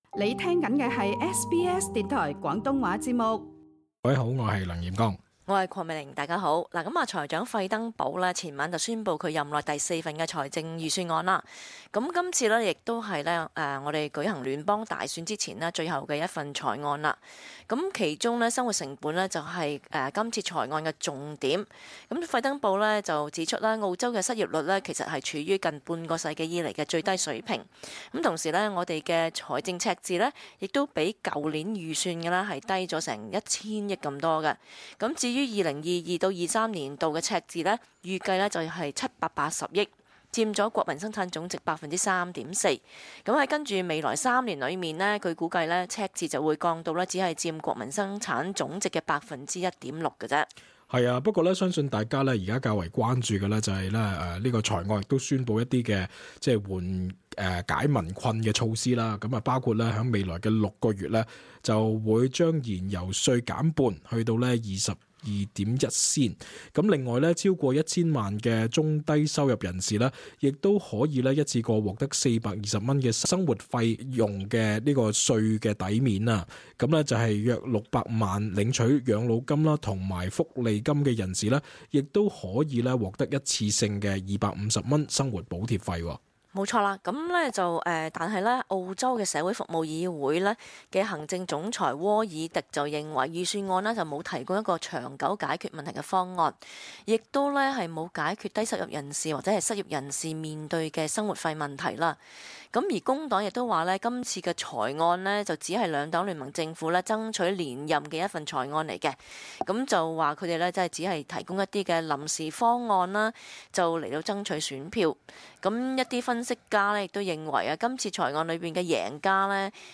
cantonese_-_talkback_-_mar_31-_final2.mp3